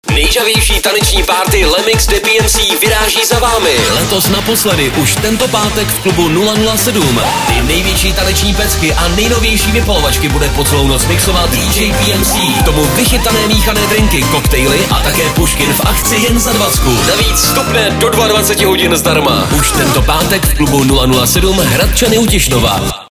pravidelná taneční párty Freeradia 107 FM - energický mix s pořádnou porcí nejžhavějších hitů